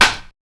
134TTCLAP1-L.wav